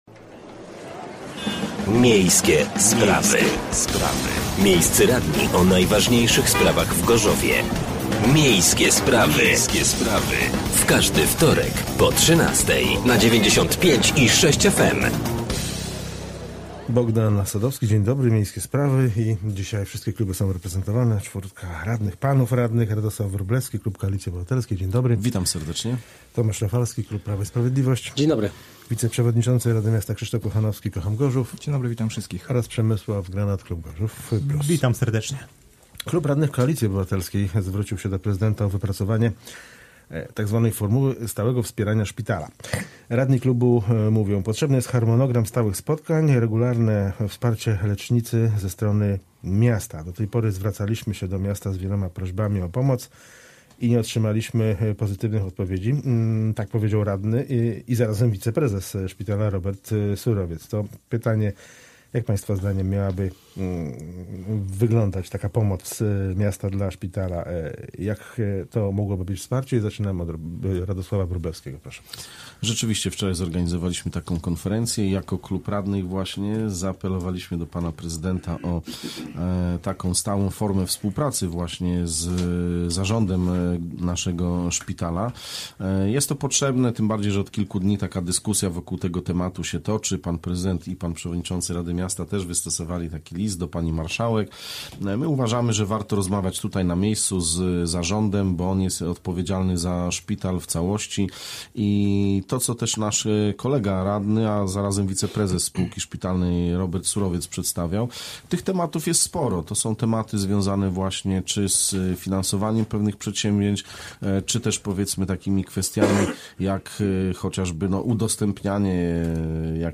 Gośćmi byli radni: Radosław Wróblewski (Koalicja Obywatelska), Tomasz Rafalski (Prawo i Sprawiedliwość) , Przemysław Granat (Gorzów Plus) i Krzysztof Kochanowski (Kocham Gorzów)